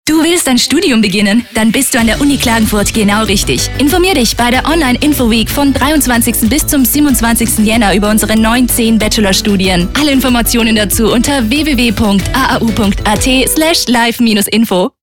Lektor
austriacki
profesjonalny lektor filmowy dostępny od ręki.